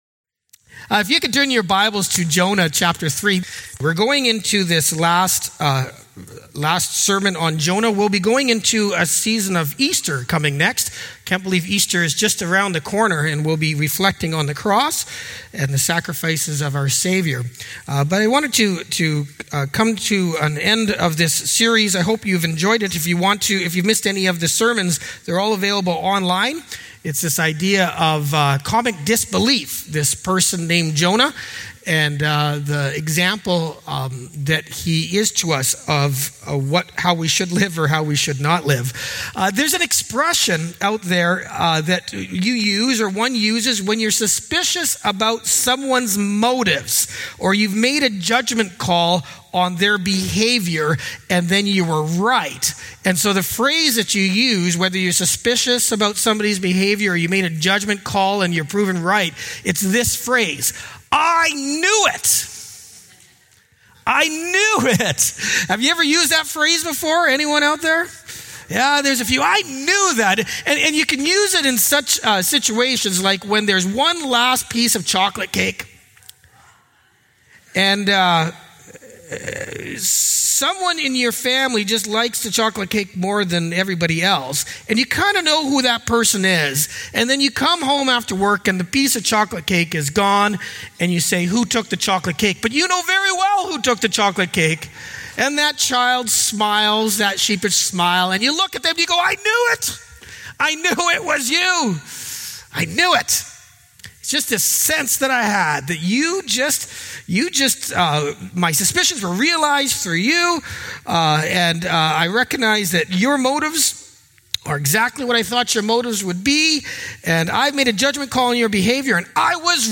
Sermons | Emmanuel Church